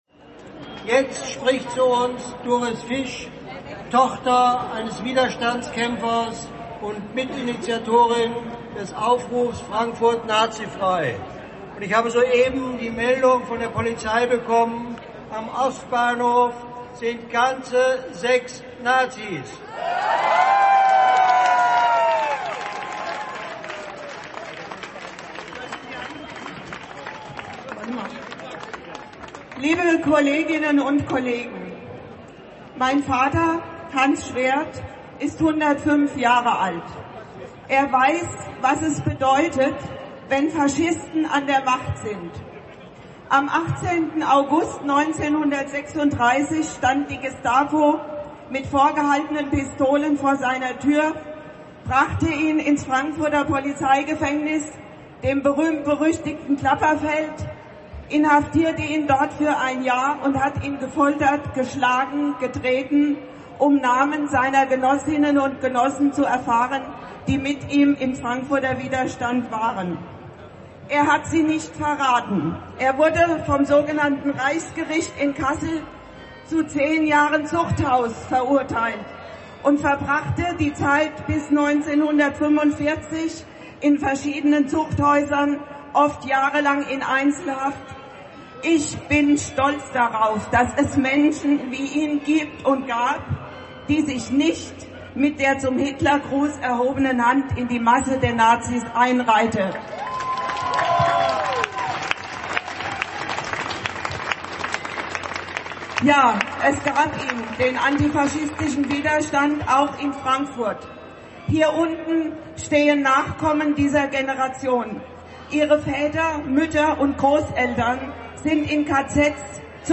Kundgebung 1.5.13 Römerberg